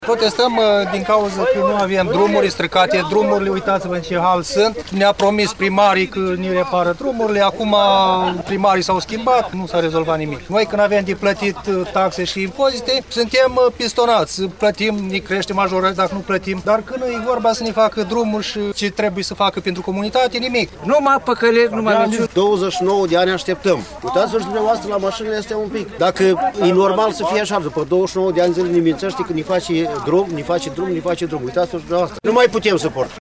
8-ian-ora-16-vox-pop-protestatari.mp3